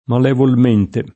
malevolo [mal$volo] agg.